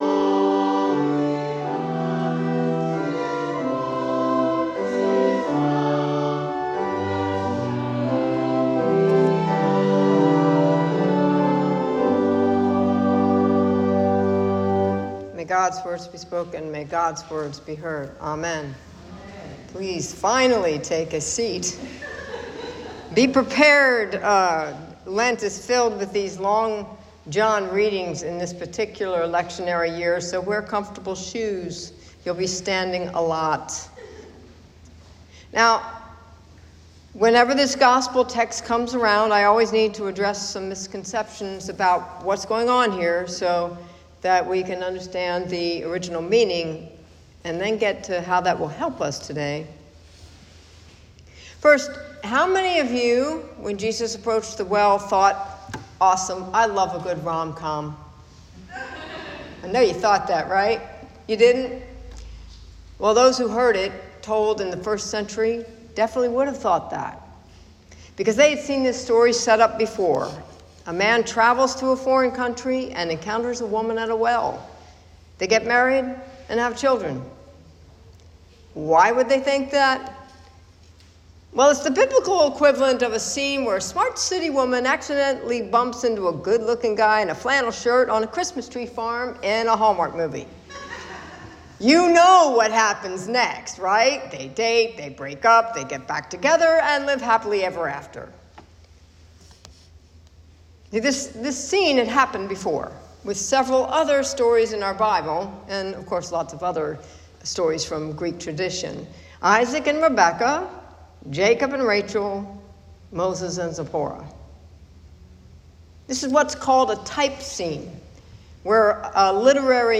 Sermon-March-8-2026.m4a